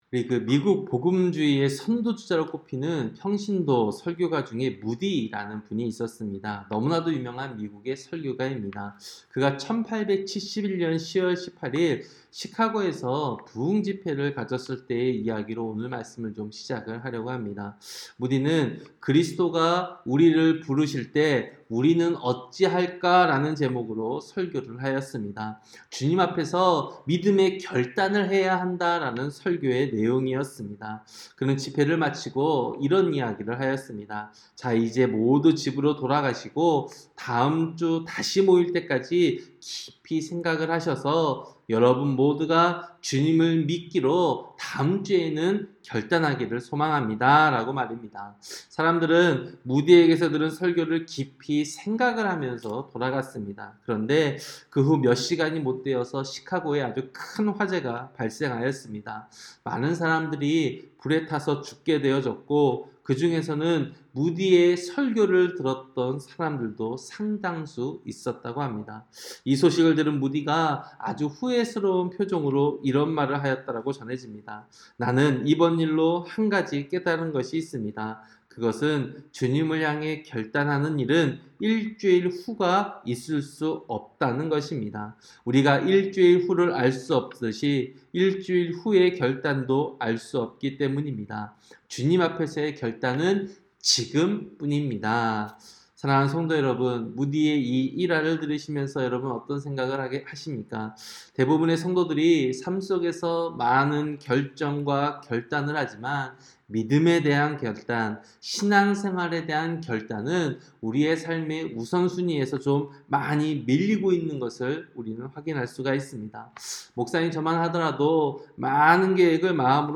새벽설교-여호수아 5장